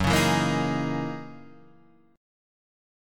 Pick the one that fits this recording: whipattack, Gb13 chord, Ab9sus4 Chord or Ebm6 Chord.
Gb13 chord